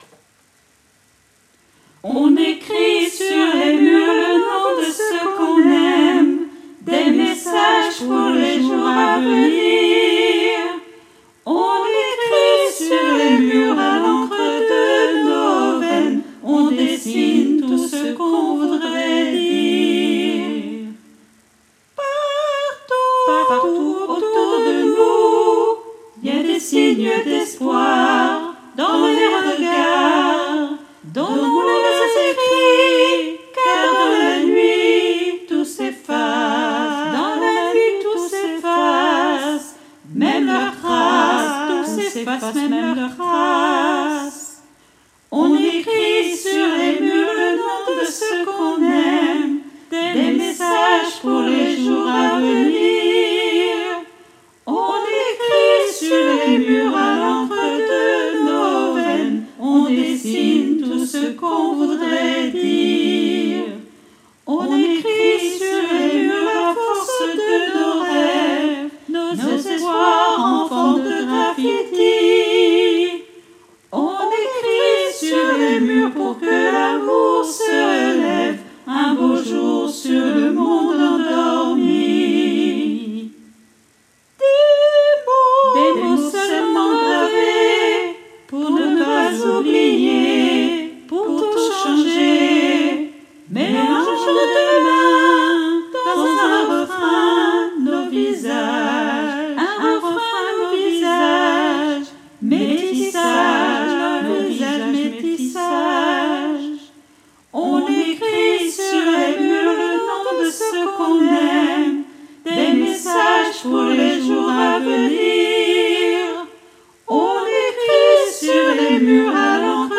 MP3 version instrumentale
Baryton